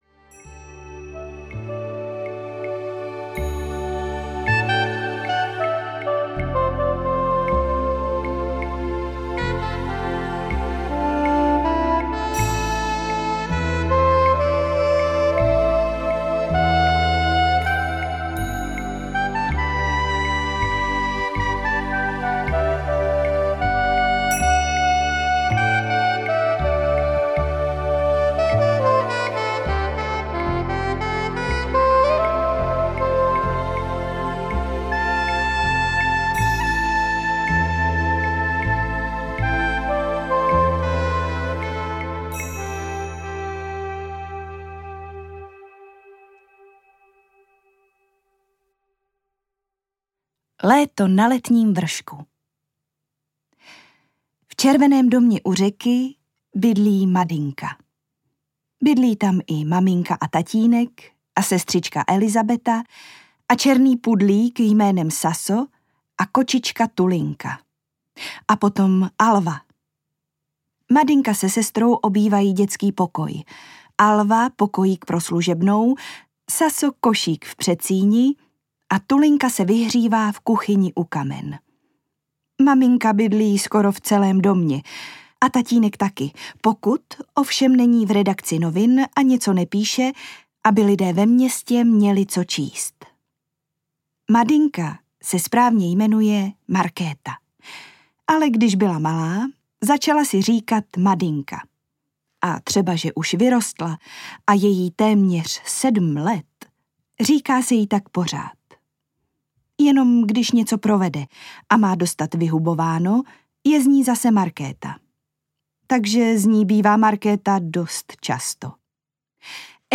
Madynka audiokniha
Ukázka z knihy